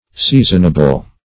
Seasonable \Sea"son*a*ble\, a.